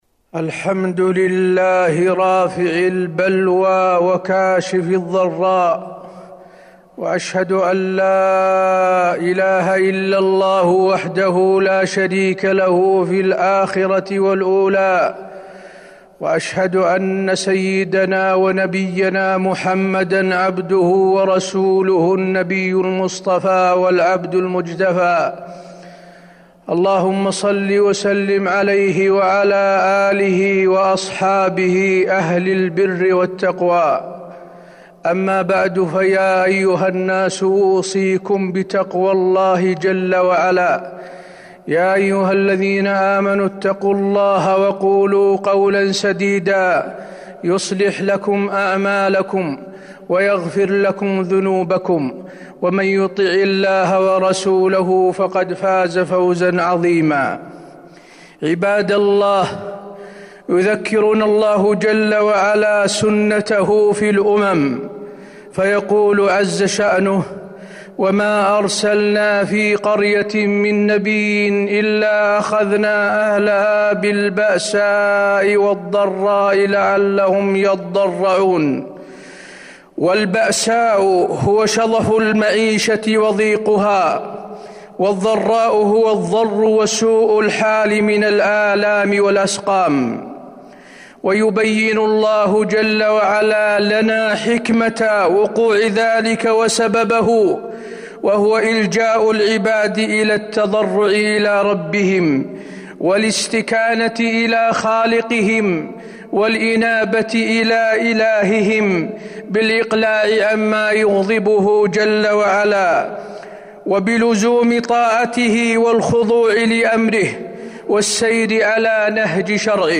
تاريخ النشر ٢٩ صفر ١٤٤٢ هـ المكان: المسجد النبوي الشيخ: فضيلة الشيخ د. حسين بن عبدالعزيز آل الشيخ فضيلة الشيخ د. حسين بن عبدالعزيز آل الشيخ الحكمة من الابتلاء